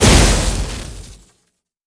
Ball lightning_Hit.wav